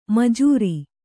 ♪ majūra